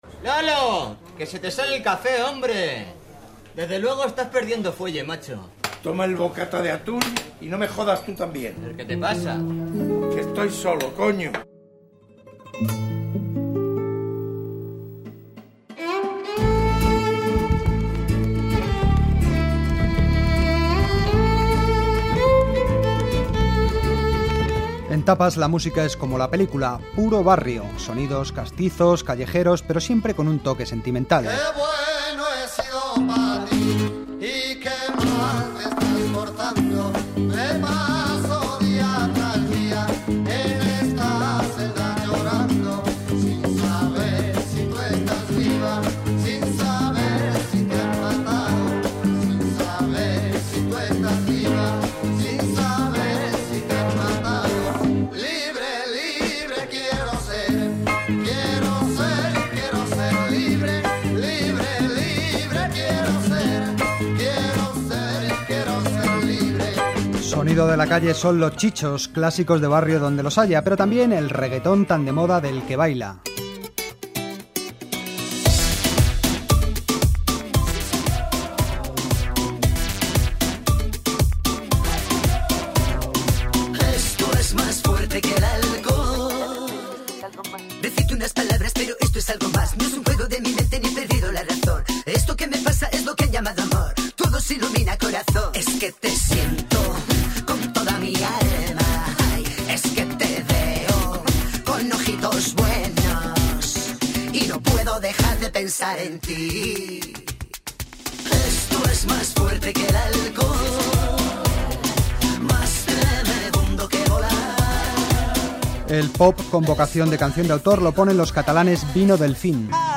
Banda sonora